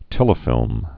(tĕlə-fĭlm)